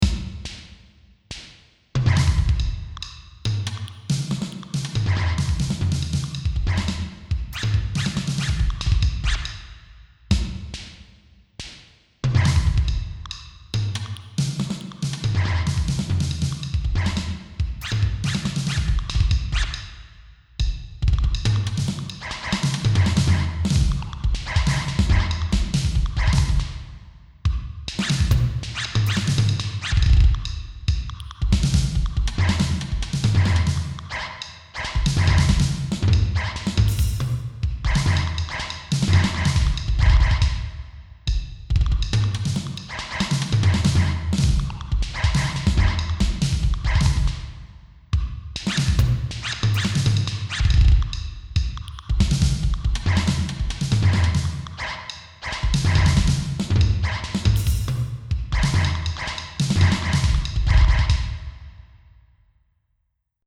Instrumental music
computer
electronic
percussion